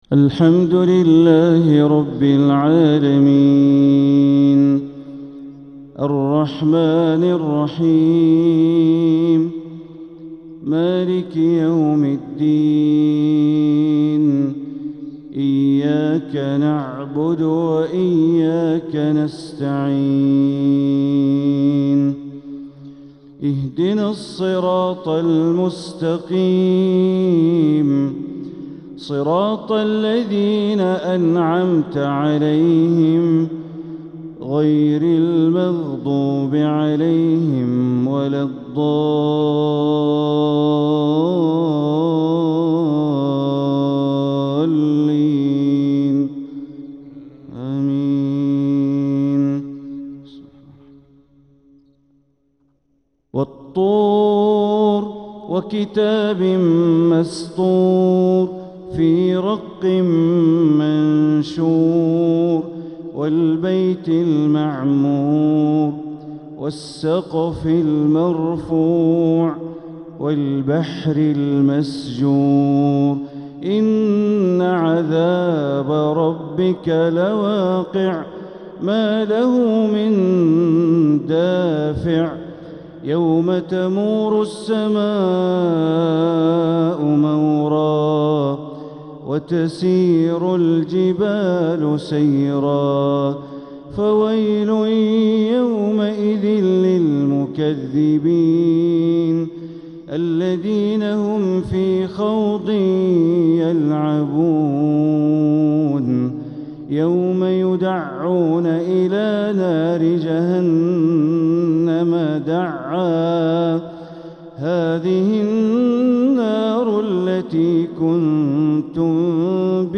تلاوة لسورة الطور كاملة | فجر الأحد 8-7-1447هـ > 1447هـ > الفروض - تلاوات بندر بليلة